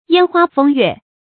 烟花风月 yān huā fēng yuè 成语解释 指男女情爱之事。